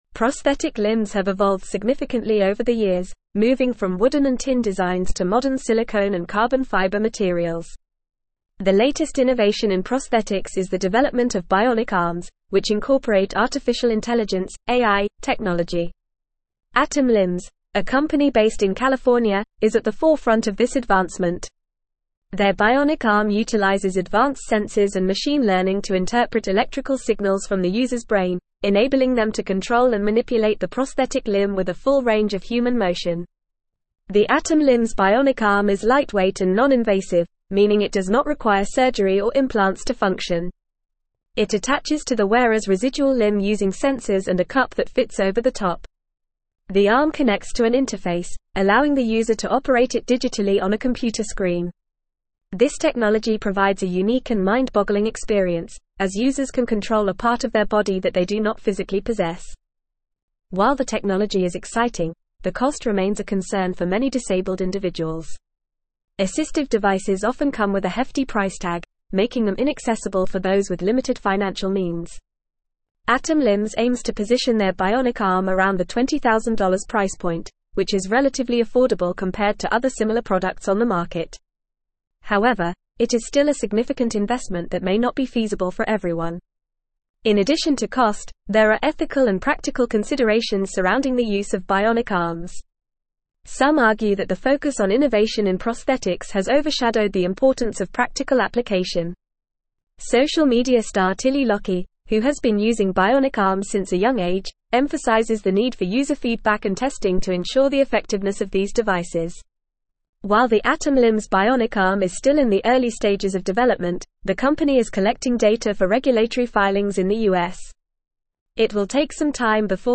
Fast
English-Newsroom-Advanced-FAST-Reading-Next-Gen-Bionic-Arm-AI-Sensors-and-Affordable-Innovation.mp3